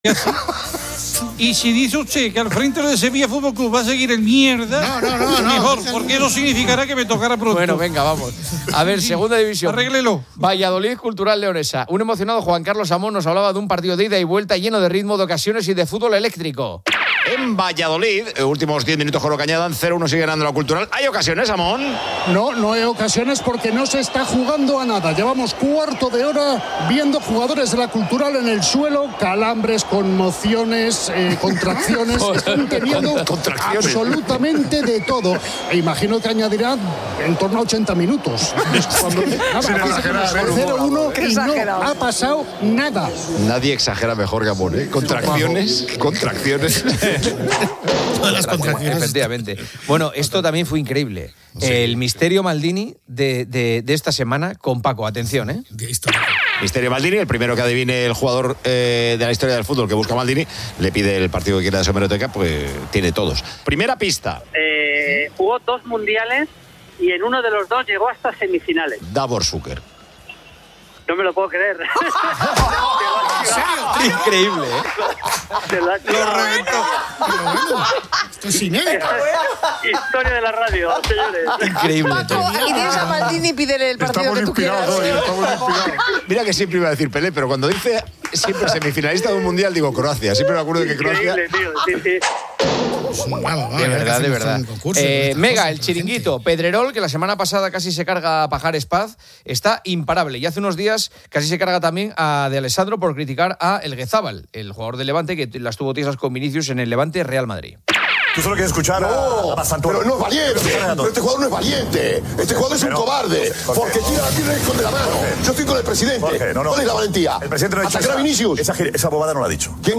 Los tertulianos de COPE debaten sobre diversos temas deportivos. Se analiza un partido de Segunda División donde se critican las interrupciones del juego y las reacciones exageradas. Se resuelve un Misterio Maldini sobre un futbolista que jugó dos Mundiales y llegó a semifinales.